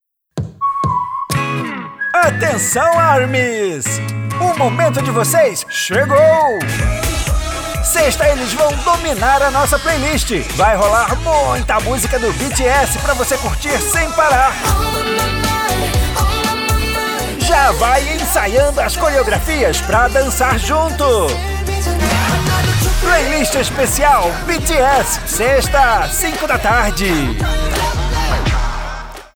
Masculino
Voz Jovem 00:29
• Tenho voz leve e versátil, e interpretação mais despojada.